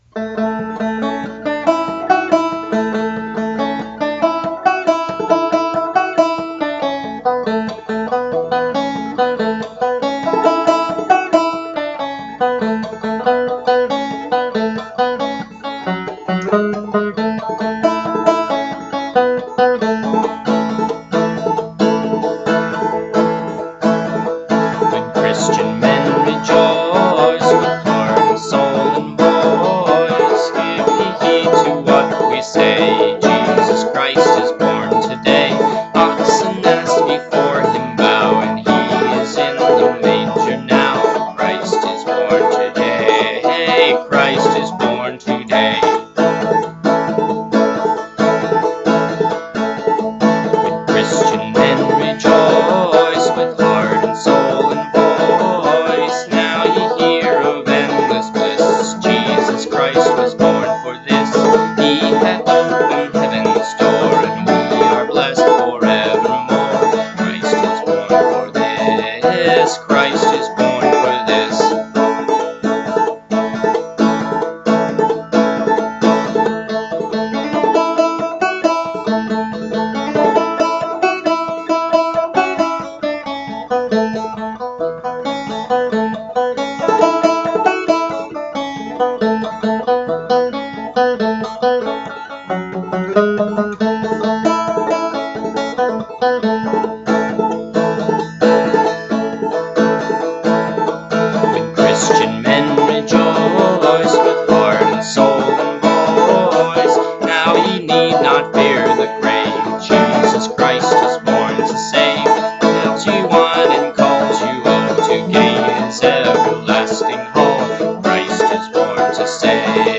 banjo and vocals
Music: IN DULCI JUBILO, German folk tune, 14th century.